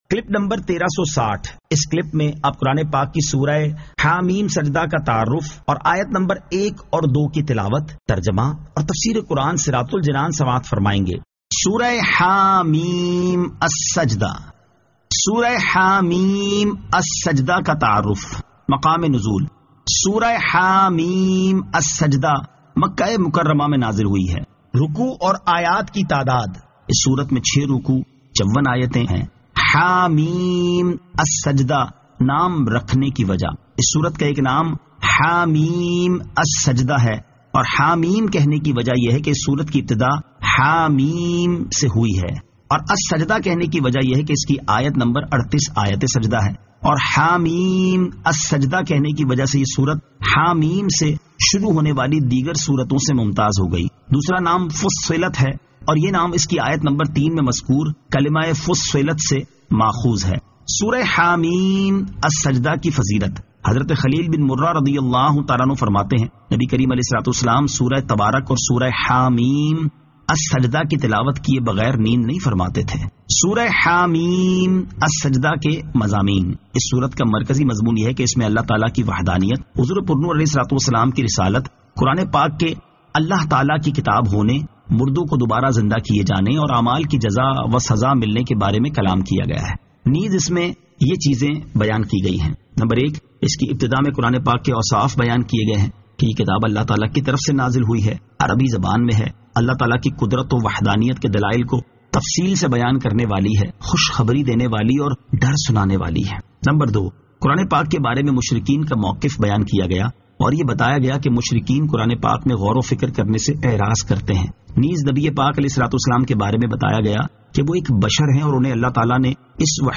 Surah Ha-Meem As-Sajdah 01 To 02 Tilawat , Tarjama , Tafseer